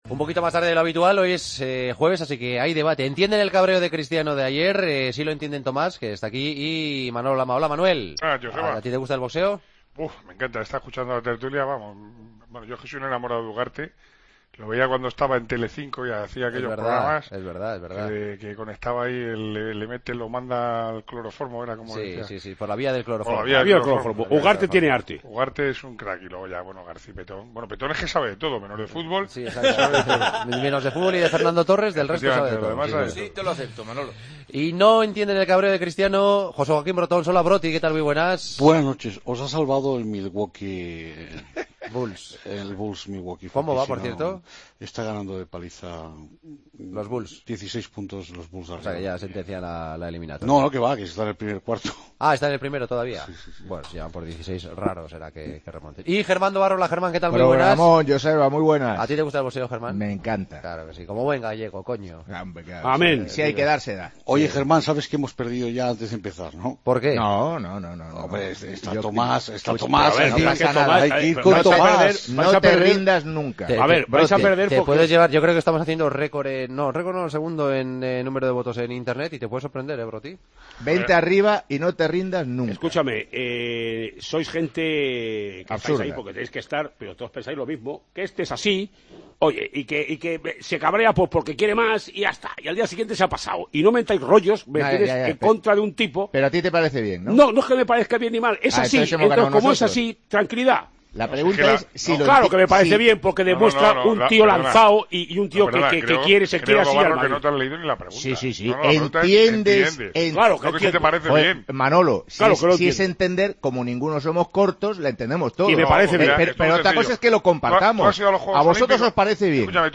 El debate de los jueves: ¿Entendéis el cabreo de Cristiano Ronaldo?